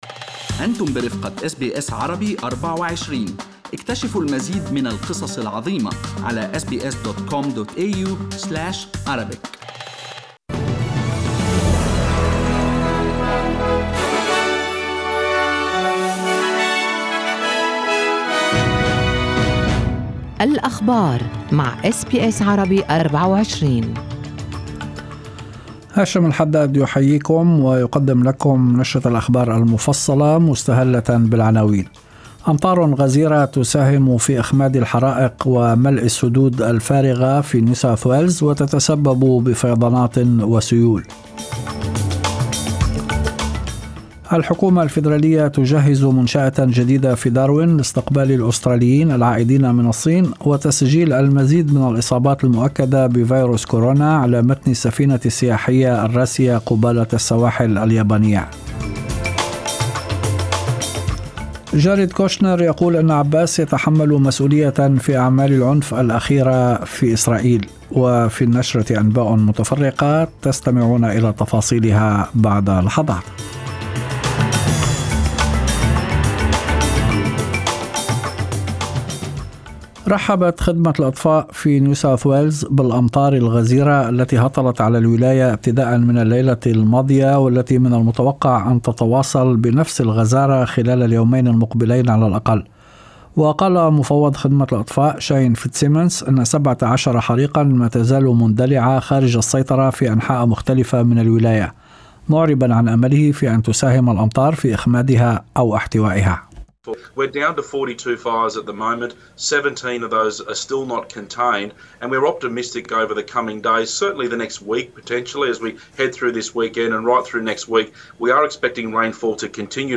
نشرة أخبار المساء 07/02/2020
Arabic News Bulletin Source: SBS Arabic24